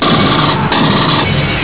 Suono dello sparo (.wav 17Kb)
pulserif.wav